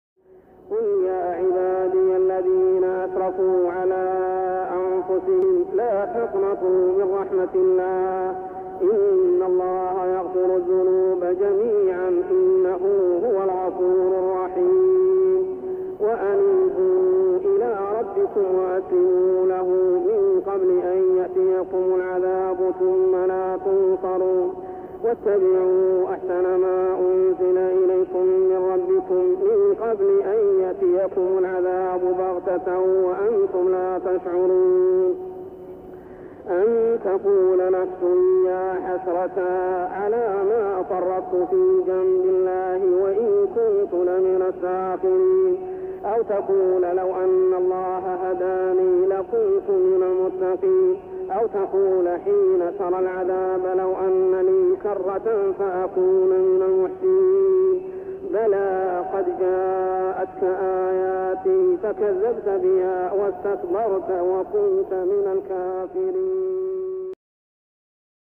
صلاة التراويح ( عام 1398هـ تقريباً ) سورة الزمر 53-59 | Tarawih prayer Surah Az-Zumar > تراويح الحرم المكي عام 1398 🕋 > التراويح - تلاوات الحرمين